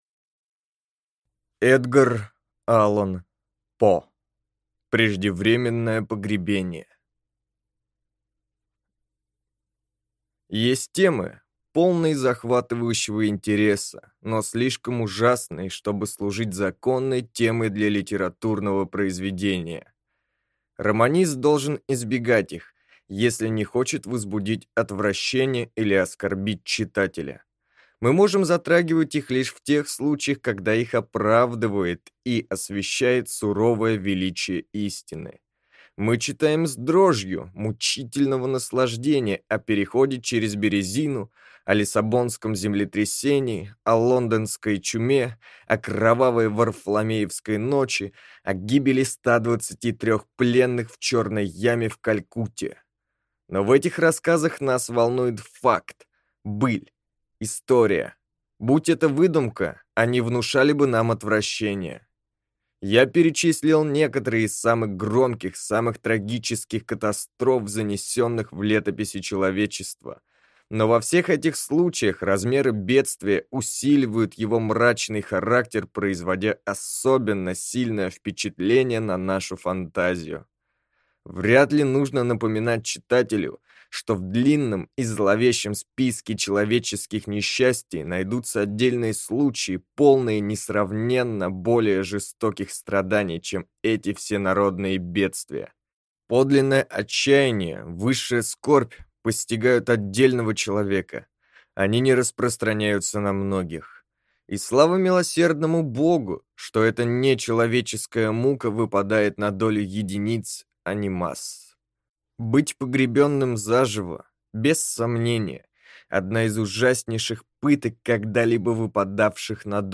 Аудиокнига Преждевременное погребение | Библиотека аудиокниг